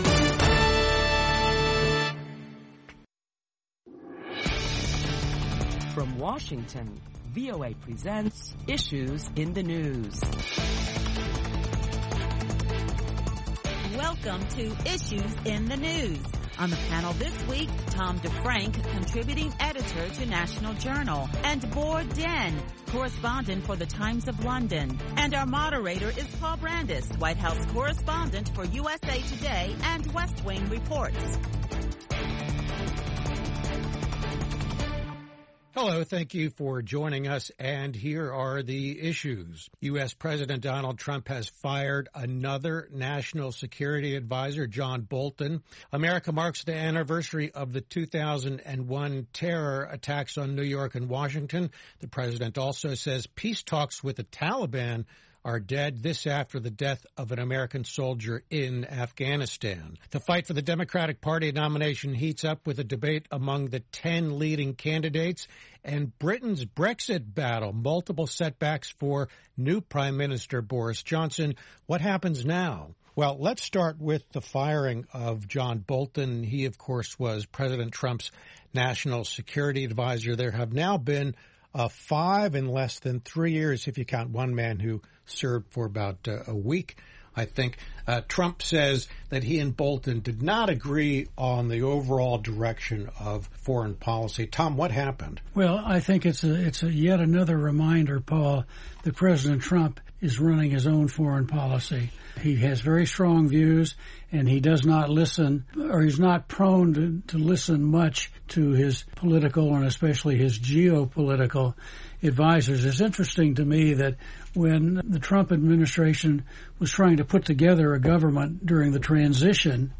Listen to a panel of prominent Washington journalists as they deliberate the latest top stories that include U.S. President Trump considers his new national security adviser…Americans paused to mark the 18th anniversary of the 9-11 terrorist attacks.